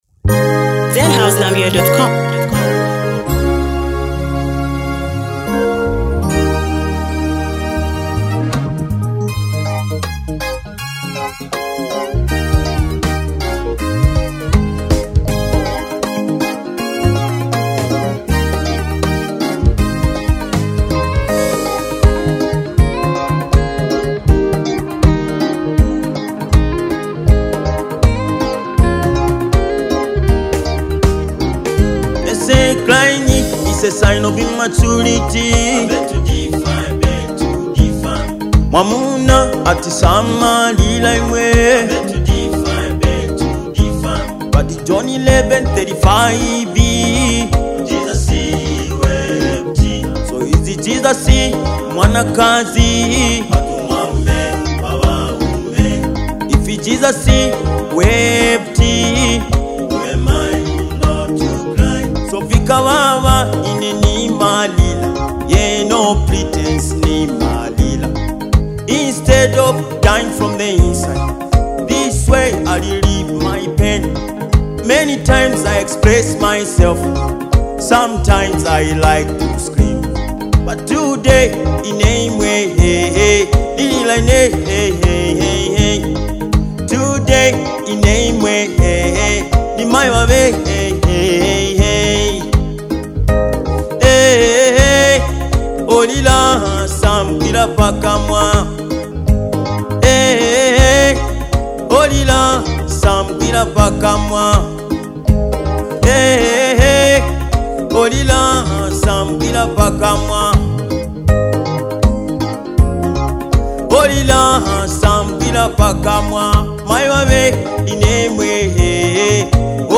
deeply moving gospel song
Through heartfelt lyrics and soulful delivery